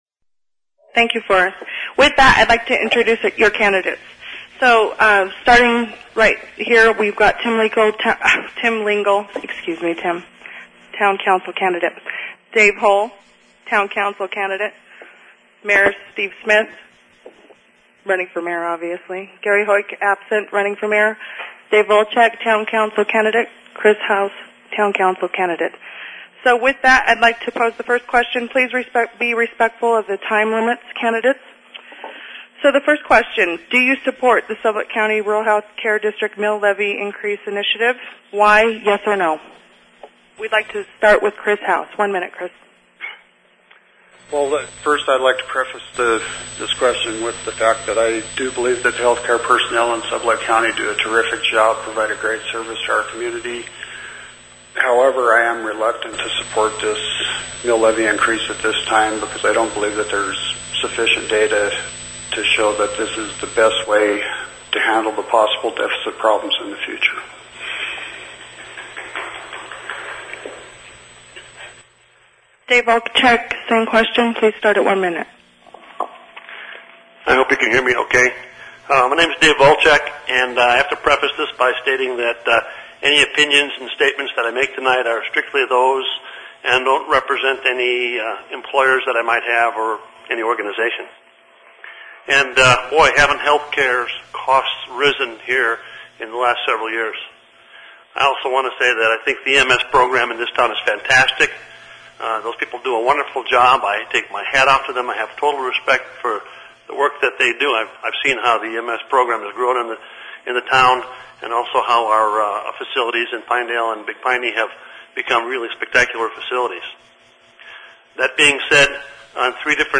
Below are the audio files from the Wednesday, April 28, 2010 Candidate Forum in the Lovatt Room of the Sublette County Library in Pinedale. Candidates for Town of Pinedale Mayor (M) and two open Town Council (TC) positions participated.